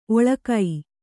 ♪ oḷakai